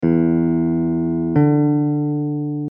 In the diagrams below, we are jumping from a note on an open string (any string will do) to another note on the same string.
Minor Seventh = 5 steps
minor-7th.mp3